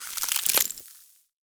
Ice (2).wav